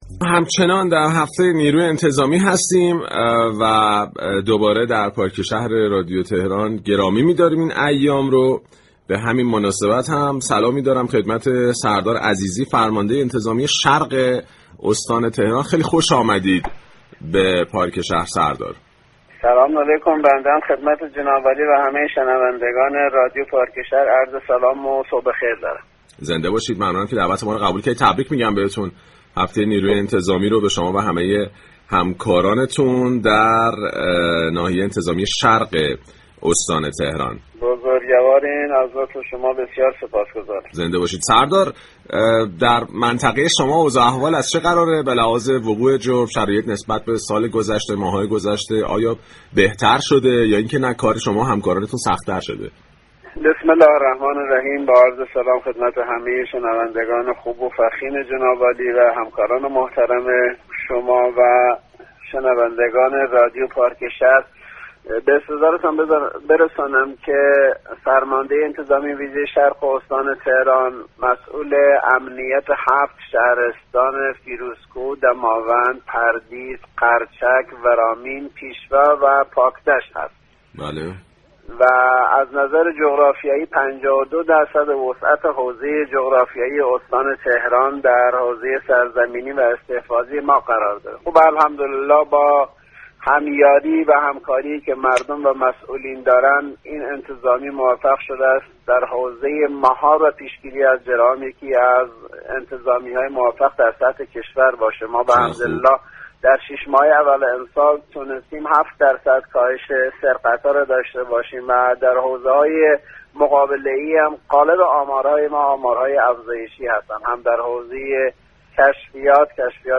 به گزارش پایگاه اطلاع رسانی رادیو تهران، سردار كیومرث عزیزی فرمانده انتظامی شرق استان تهران در گفتگو با پارك شهر گفت: فرمانده انتظامی ویژه شرق استان تهران، مسئول امنیت هفت شهرستان فیروزكوه، دماوند، پردیس، قرچك، ورامین، پیشوا و پاكدشت است كه نظر جغرافیایی 52 درصد وسعت استان تهران را تشكیل می دهد.